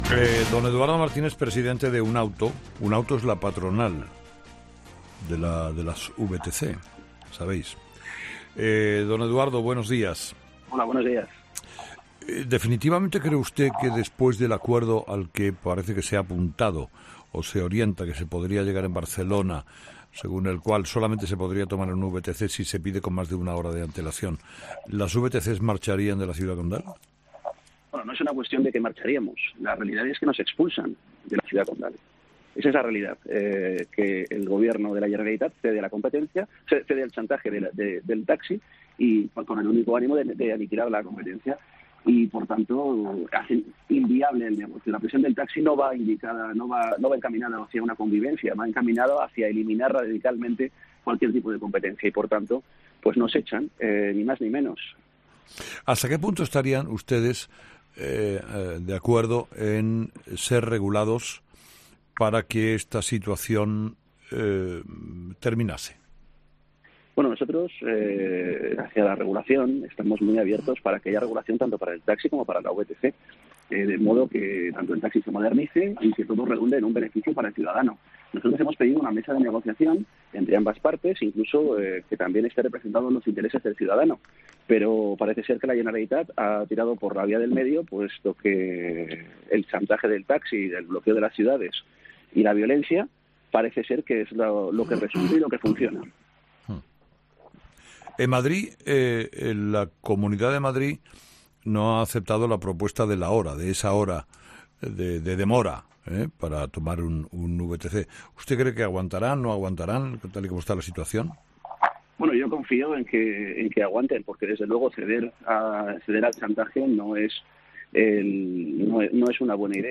Entrevistado:
Representantes del sector de los vehículos de alquiler con conductor, los VTC que usan plataformas como Uber y Cabify, anunciaron este martes que se irán de Barcelona cuando se apruebe el decreto ley de la Generalitat que obligará a precontratar este tipo de servicios con una hora de antelación. Este miércoles en 'Herrera en COPE', sus representantes han aclarado que se trataría de "una expulsión" ante el chantaje que ha aceptado la Generalitat.